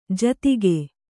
♪ jatige